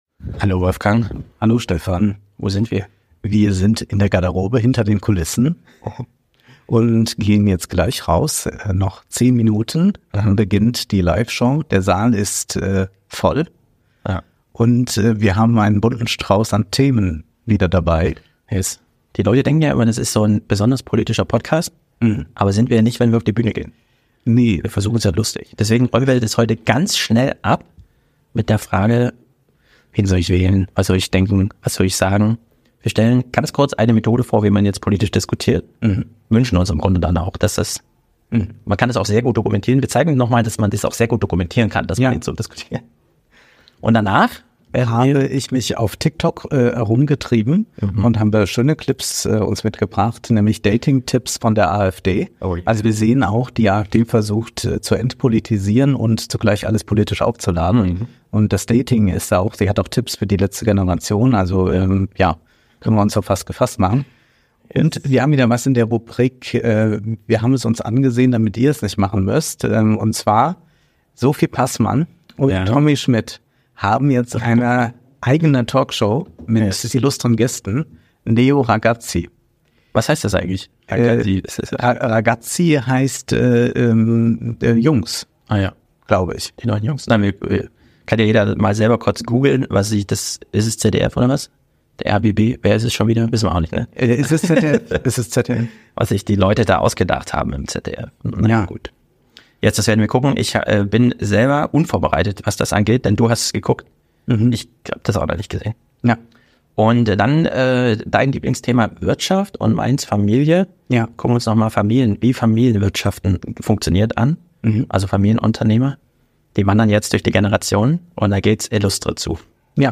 Live im Salon - Garderobengespräch vorab